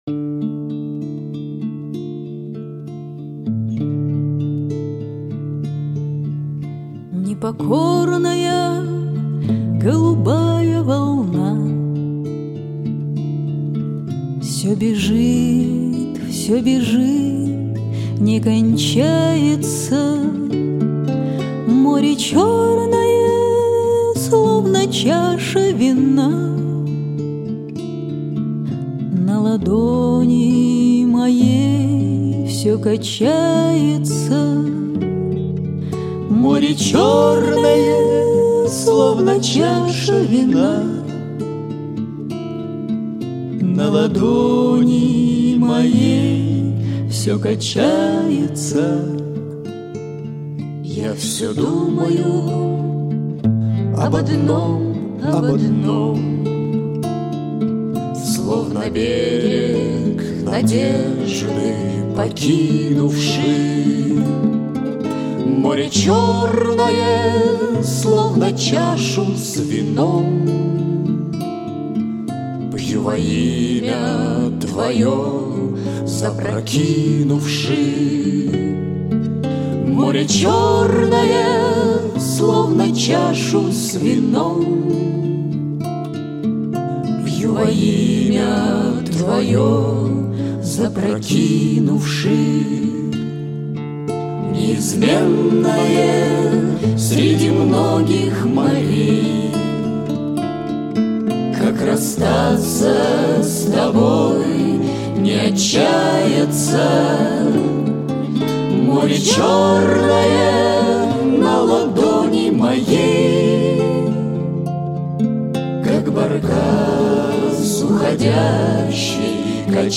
Нашла у себя их исполнение получше качеством